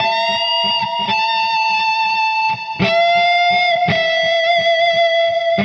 Index of /musicradar/80s-heat-samples/85bpm